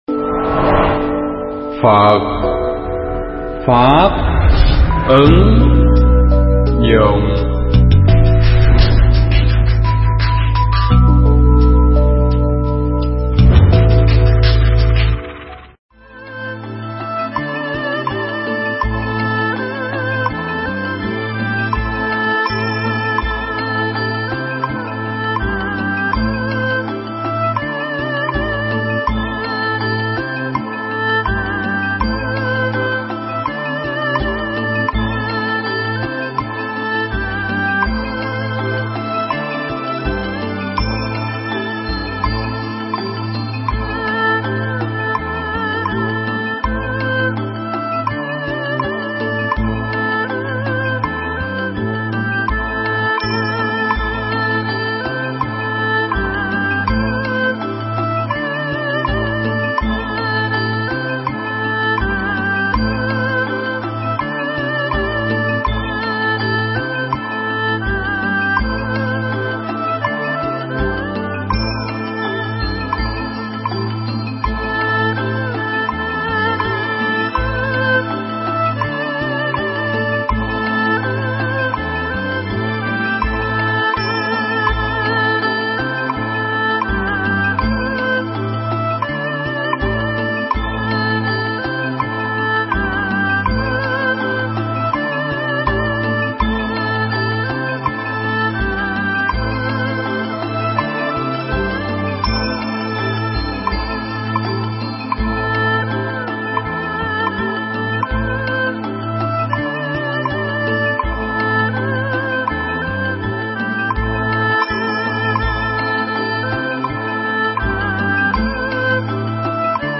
Pháp thoại Phật Và Thần english sub
giảng tại chùa Vô Ưu - Bình Tân, TP. HCM